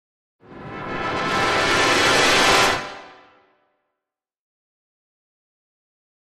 Brass Section, Tension Crescendo - "Danger Approach", Type 4